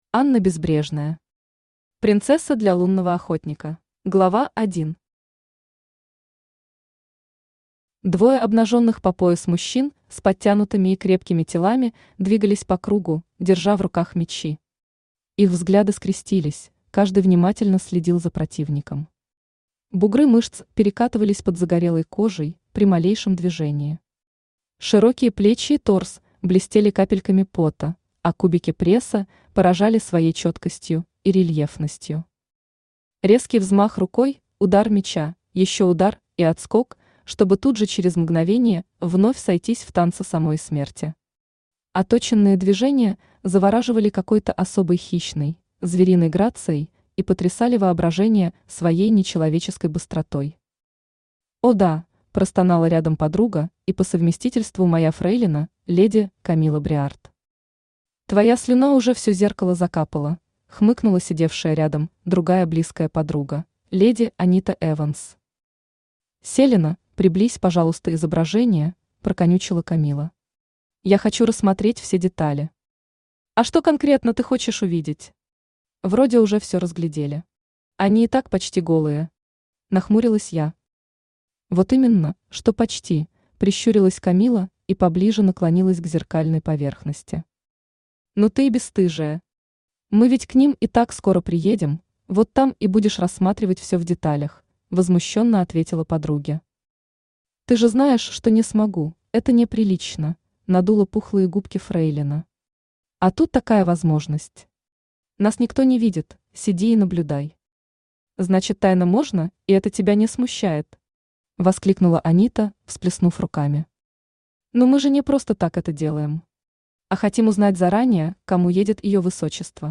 Аудиокнига Принцесса для лунного охотника | Библиотека аудиокниг
Aудиокнига Принцесса для лунного охотника Автор Анна Безбрежная Читает аудиокнигу Авточтец ЛитРес.